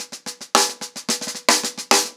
TheQuest-110BPM.13.wav